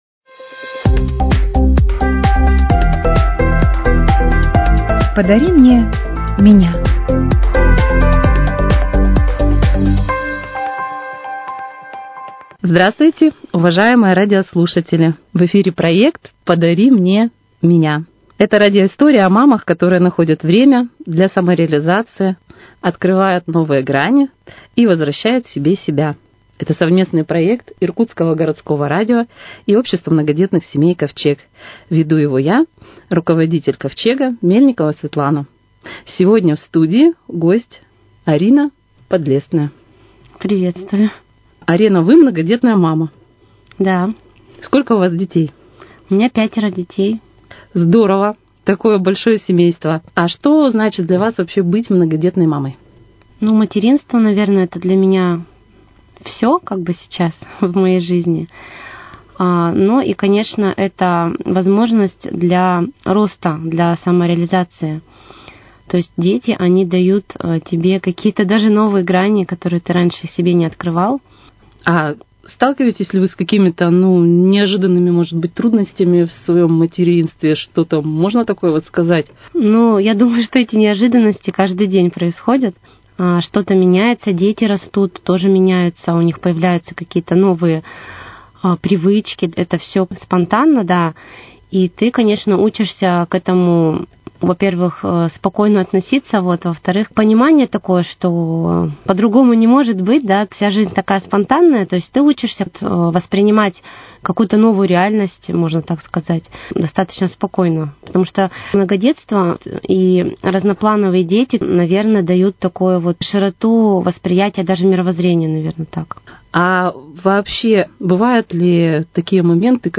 Вашему вниманию новый, уже третий выпуск передачи "Подари мне меня". Это радио-истории о многодетных мамах, которые в повседневных заботах не забывают о себе, развиваются, творят и вдохновляют своим примером других.